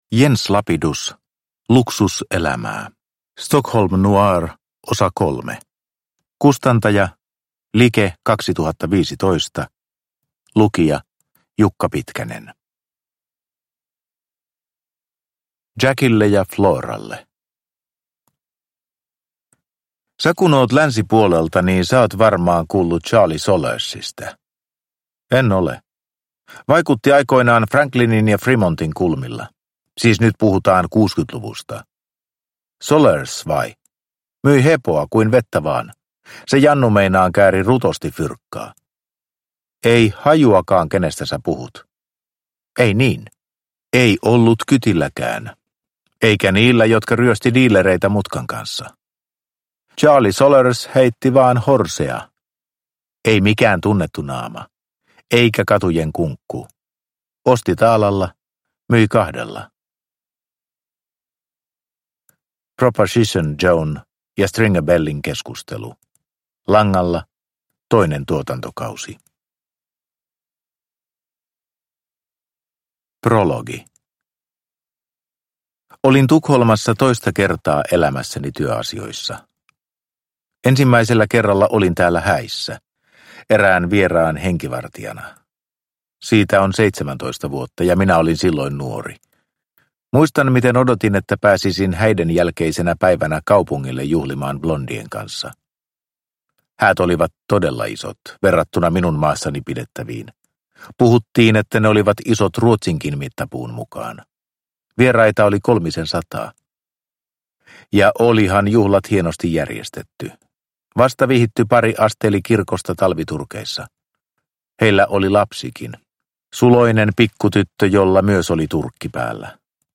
Luksuselämää – Ljudbok – Laddas ner